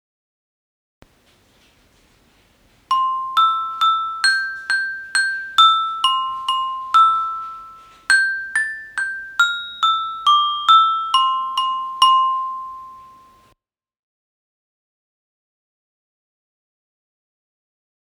Imos practicar auditivamente o novo compás que estamos traballando: o 6/8.
Ditado rítmico-melódico:
dictadoritmelodico.mp3